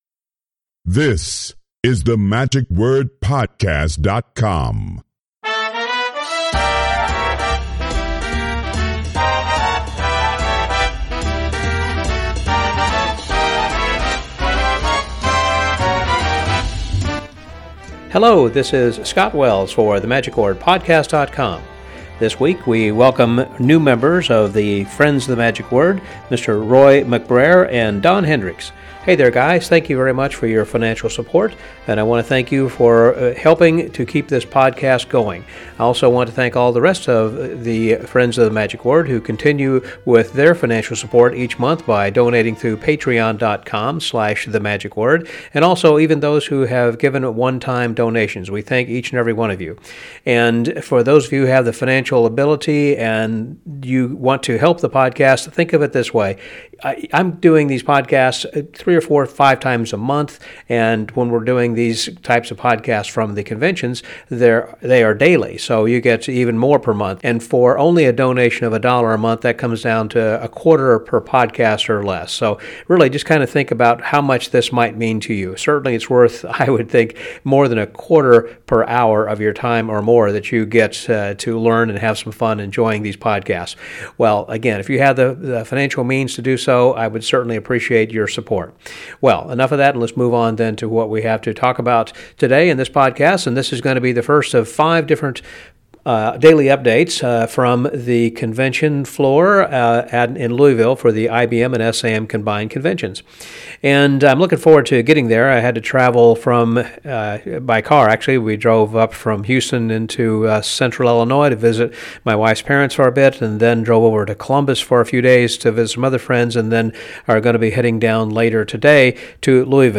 This is the first of a five day series of podcasts from Louisville featuring conversations with convention talent, organizers, dealers and registrants.